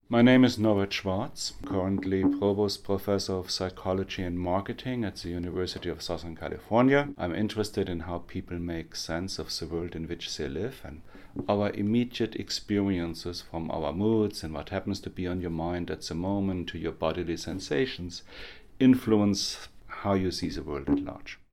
Dr. Schwarz introduces himself: